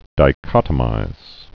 (dī-kŏtə-mīz)